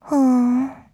Ashley's voice from the official Japanese site for WarioWare: Move It!
WWMI_JP_Site_Ashley_Voice.wav